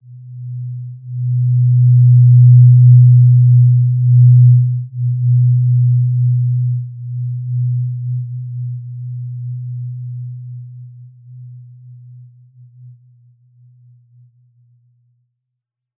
Basic-Tone-B2-mf.wav